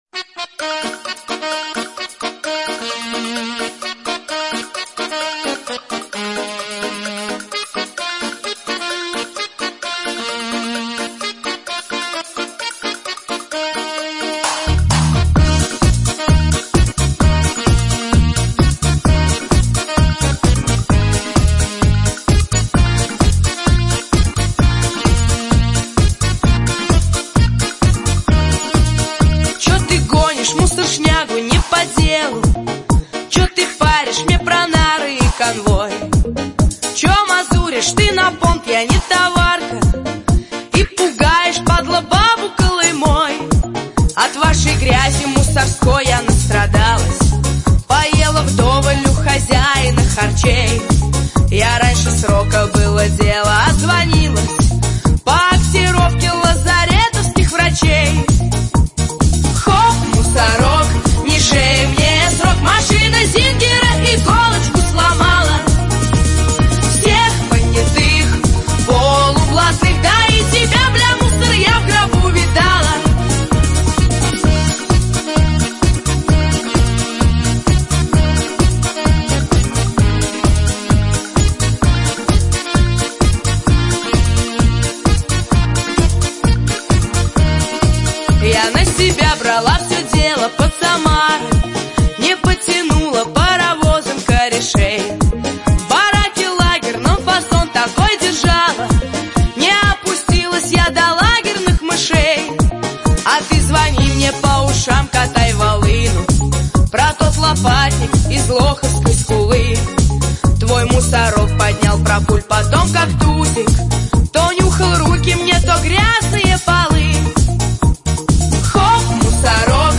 • Качество: 64, Stereo
девочки поют о своей не легкой доли